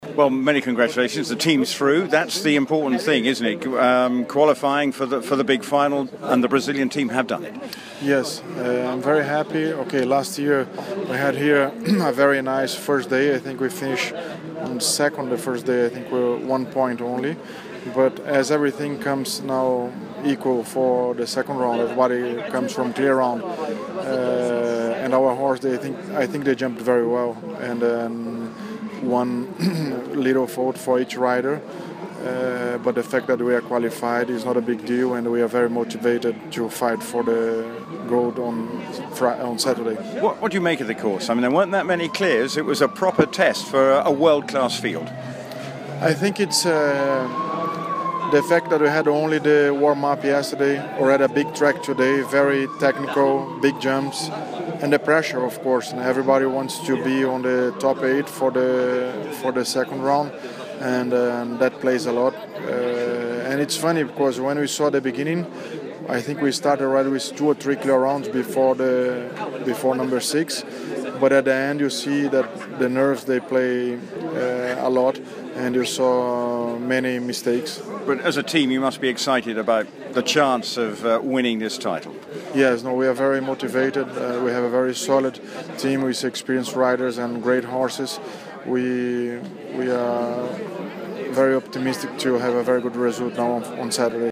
Interview with Brazillian rider Doda De Miranda